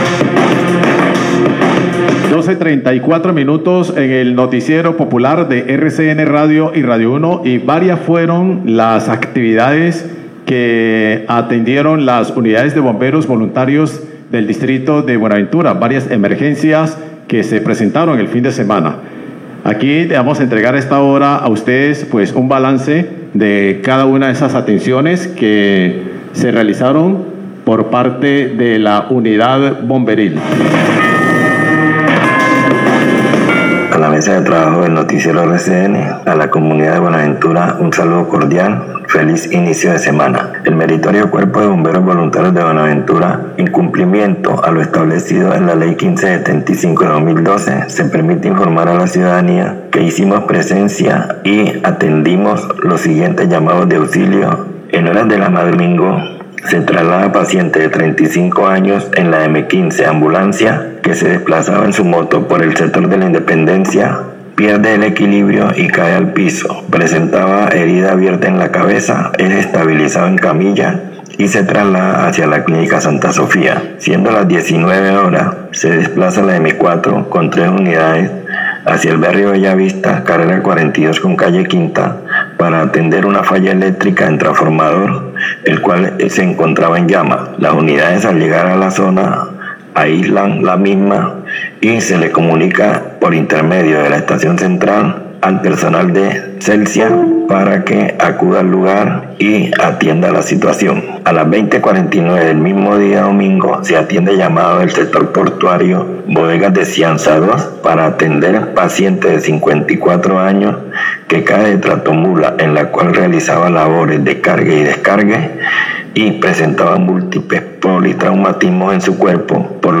Reporte Bomberos Buenaventura por emergencia con transformador en el barrio Bellavista
Radio